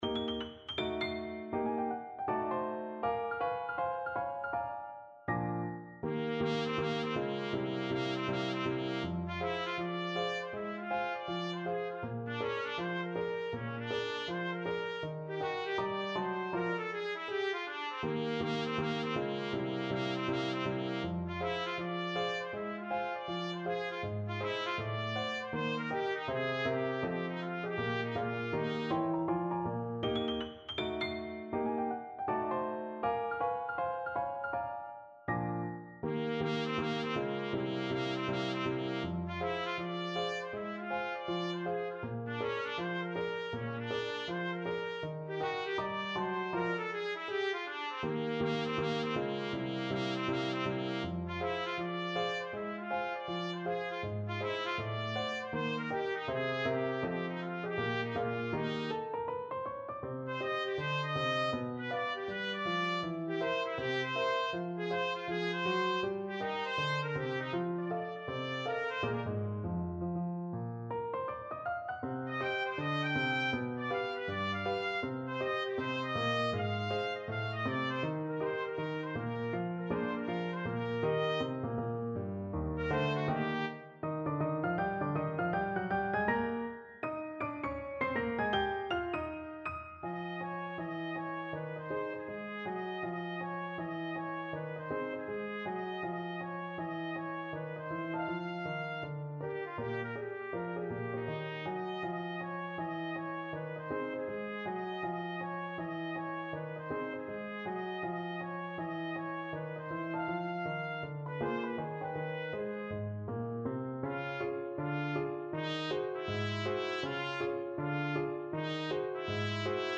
2/2 (View more 2/2 Music)
= 80 Swung
Jazz (View more Jazz Trumpet Music)